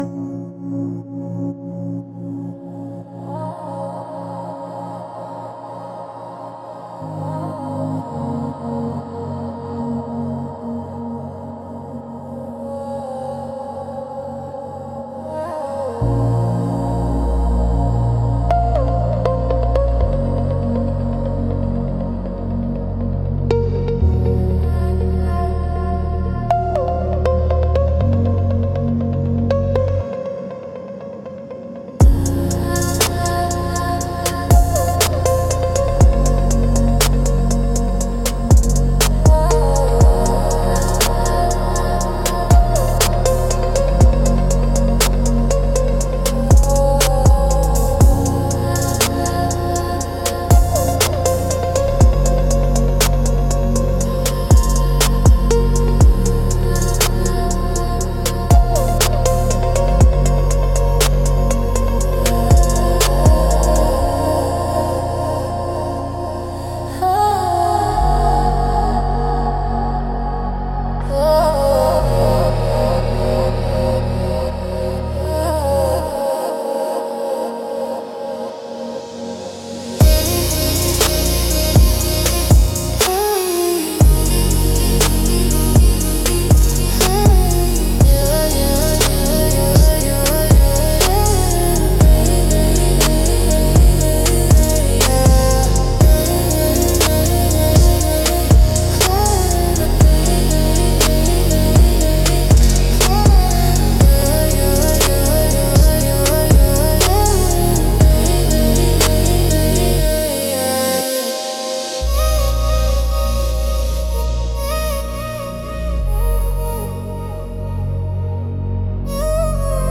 Instrumental - Shimmer crush 3.19